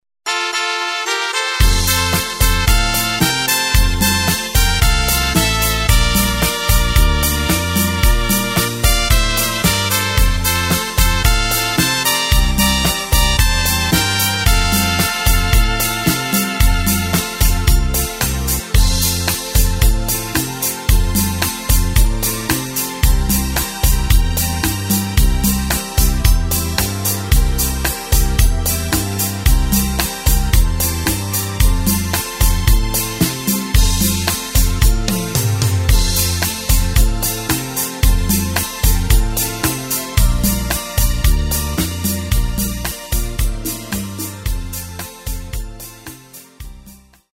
Takt:          4/4
Tempo:         112.00
Tonart:            F
Schlager Rumba aus dem Jahr 2024!
Playback mp3 mit Lyrics